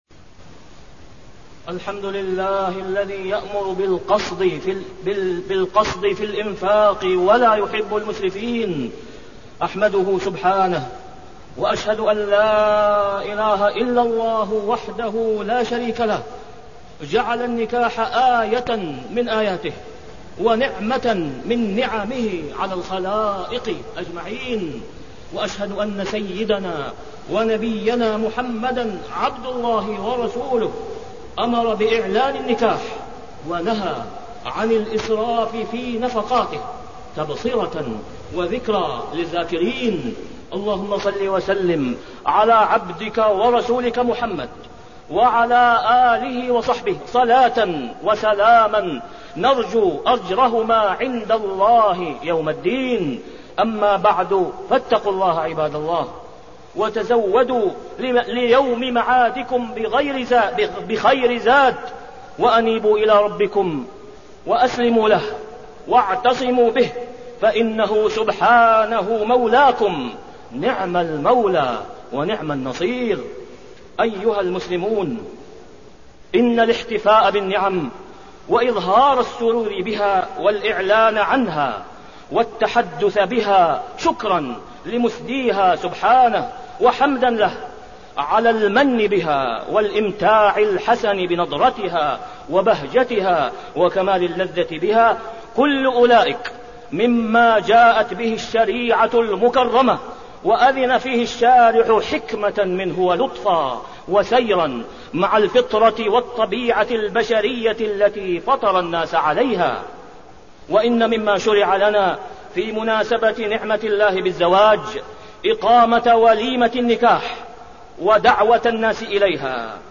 تاريخ النشر ٢٤ رجب ١٤٣٠ هـ المكان: المسجد الحرام الشيخ: فضيلة الشيخ د. أسامة بن عبدالله خياط فضيلة الشيخ د. أسامة بن عبدالله خياط التحذير من الإسراف خاصة في النكاح